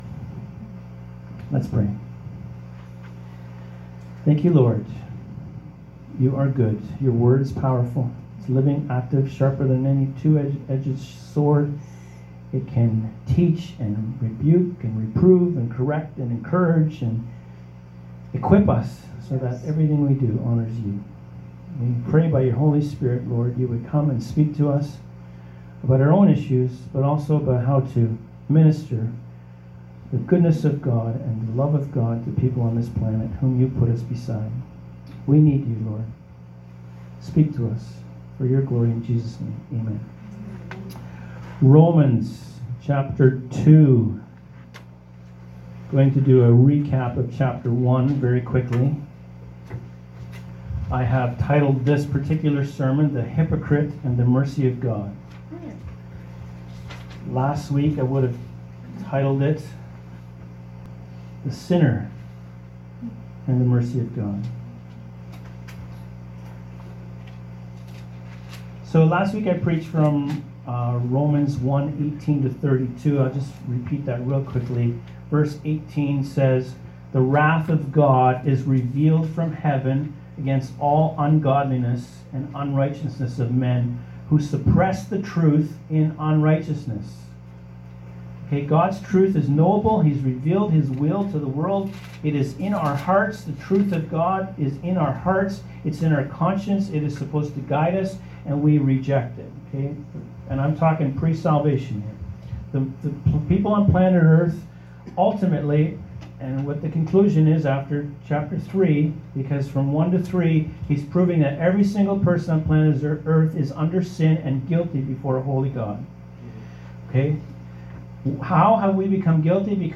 Audio Sermons - Freedom House Church and Healing Centre